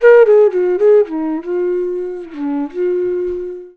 FLUTE-B11 -L.wav